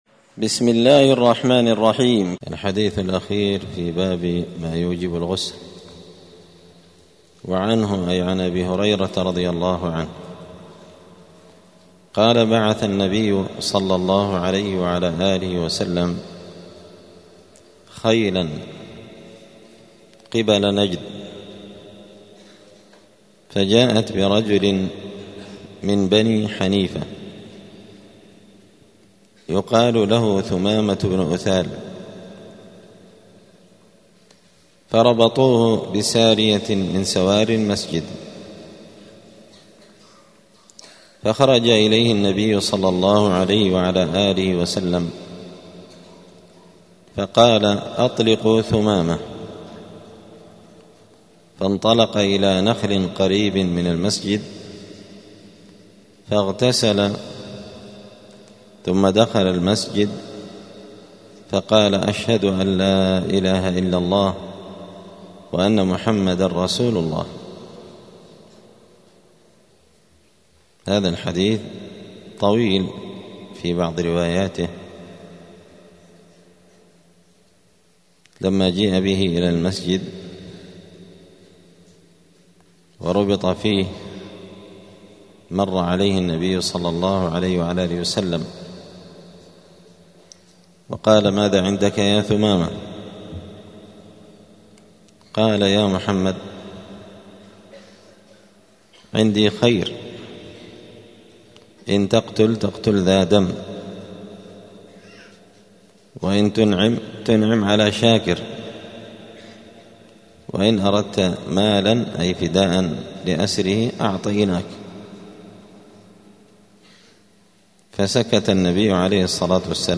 دار الحديث السلفية بمسجد الفرقان قشن المهرة اليمن
*الدرس الثامن والسبعون [78] {باب مايوجب الغسل حكم الغسل الكافر اذا أسلم}*